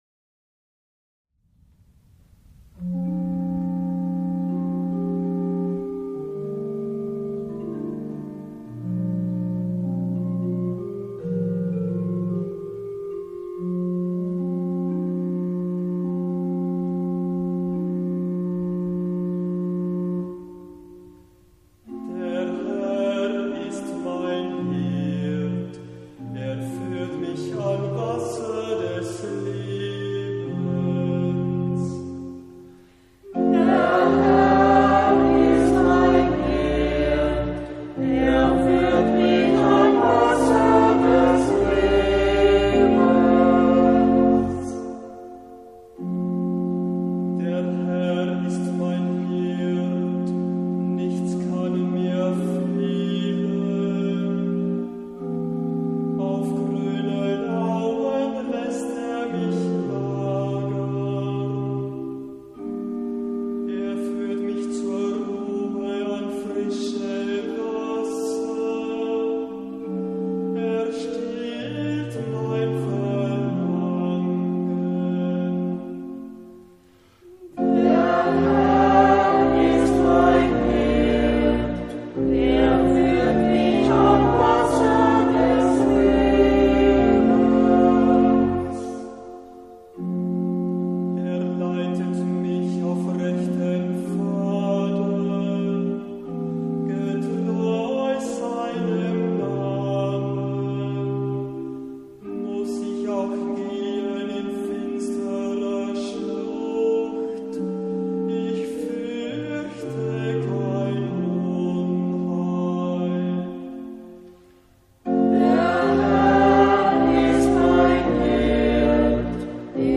Hörbeispiele aus verschiedenen Kantorenbüchern
Psalmen aus dem Gurker Psalter für Kantor mit Orgel- oder Gitarrenbegleitung finden Sie hier. geordnet nach den Lesejahren ABC und den Festen bzw.